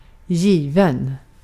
Ääntäminen
US : IPA : [ˈgɪvən]